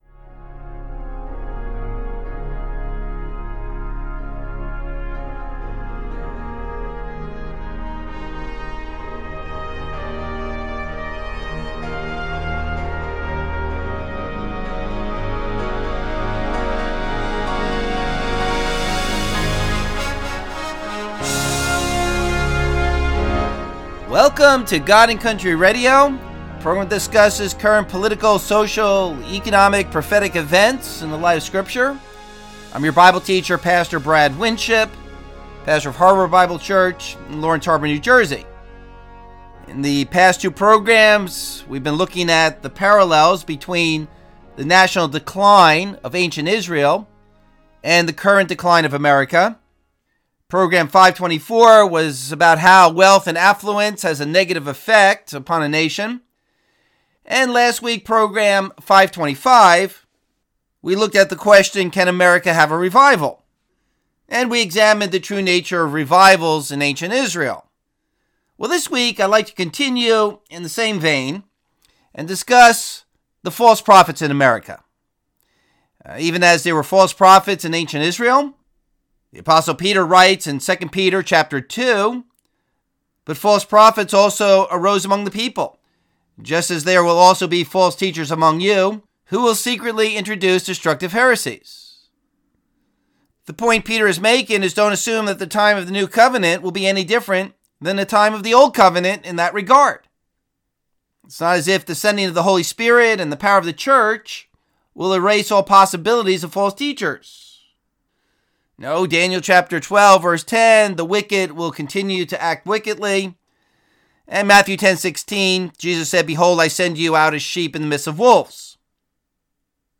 Abridged Radio Program